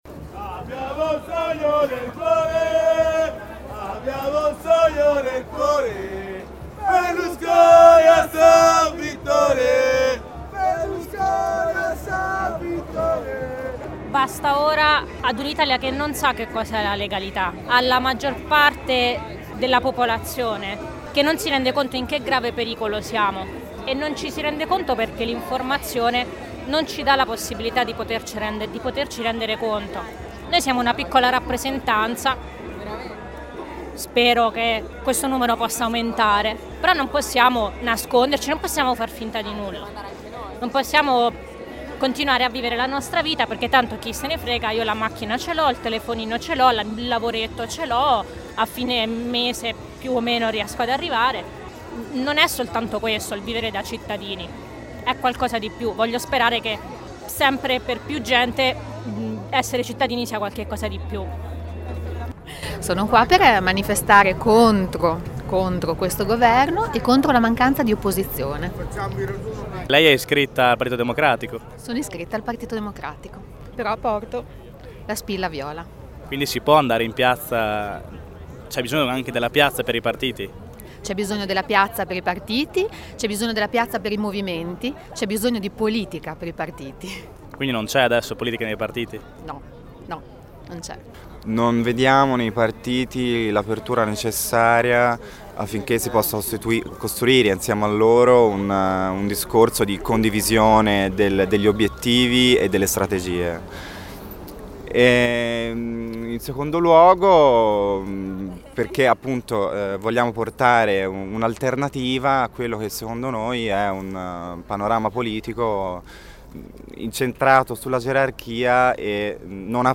Voglia di fare politica anche sabato 13 marzo, in Piazza XX settembre, al presidio e al corteo organizzati dal Popolo Viola di Bologna. Alcune voci raccolte dalla piazza.